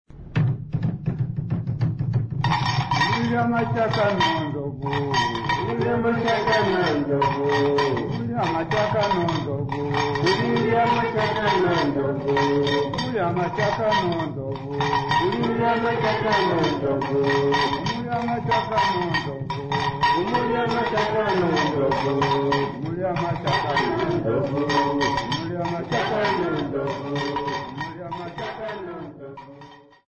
Sambiu church music workshop participants
Sacred music Namibia
Mbira music Namibia
Africa Namibia Sambiu mission, Okavango sx
field recordings
Church song with drum and tin type instrument accompaniment.